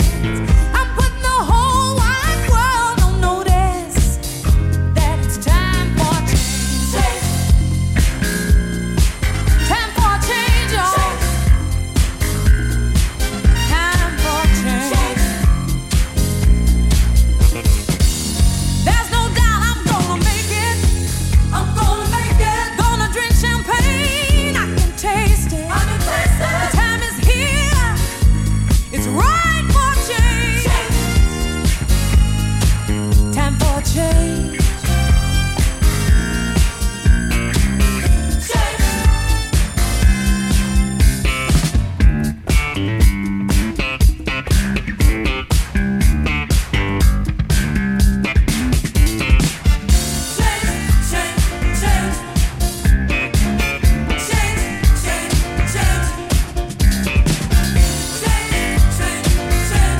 Disco Soul